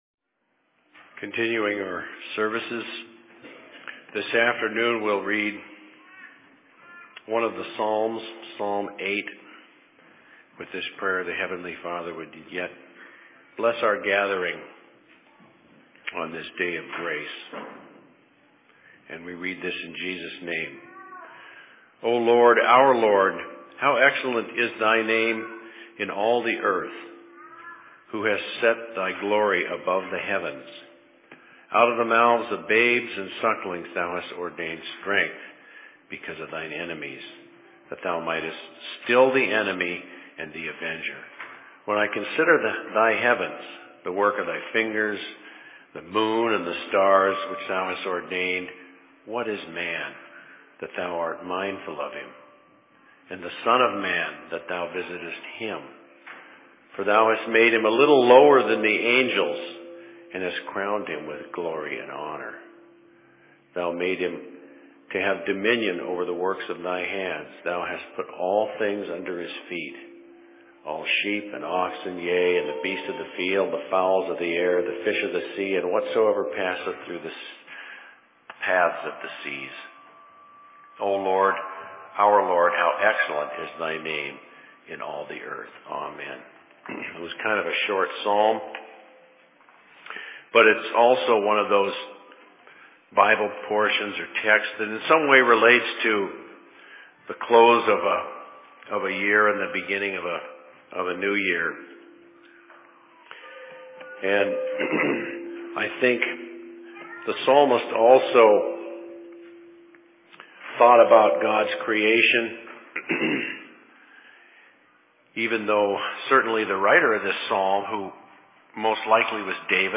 Sermon in Seattle 05.01.2014
Location: LLC Seattle